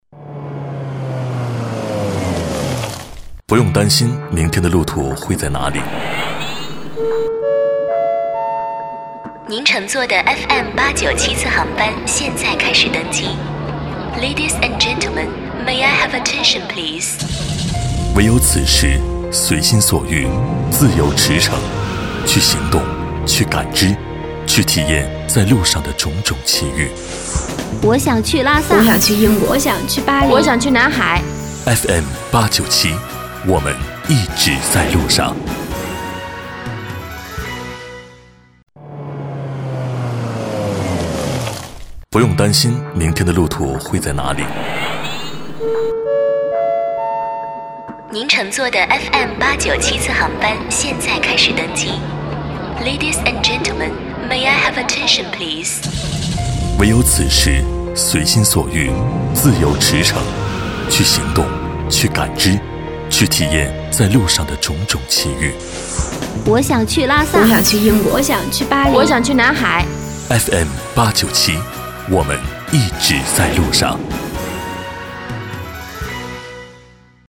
• 男S310 国语 男声 广告-湖南广播-电台-大气、时尚 大气浑厚磁性|积极向上